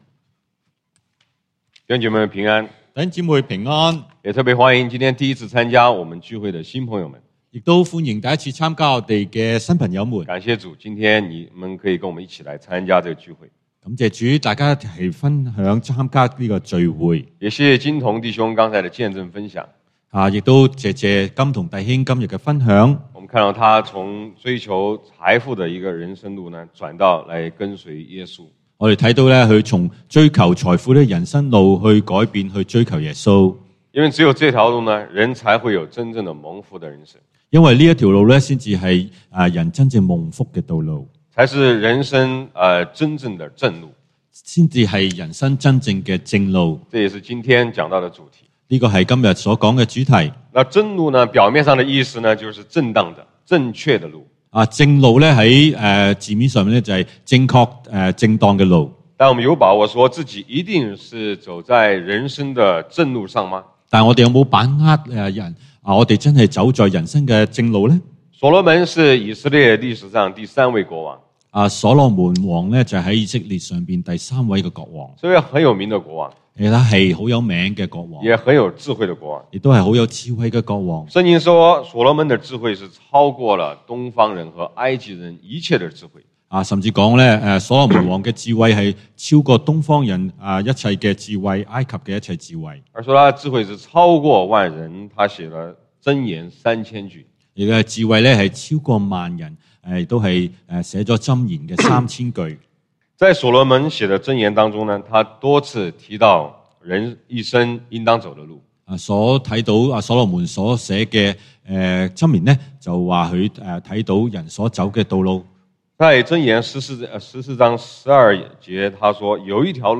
講道經文：箴言 Proverbs 14:12；約翰福音 John 14:6)